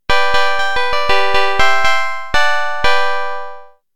I have a simple virtual analog synthesizer going that can be used to synthesize piano-like sounds.
Sample 4 (G minor)
sample-gminor.mp3